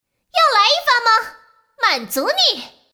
女声
王者荣耀角色模仿-15孙尚香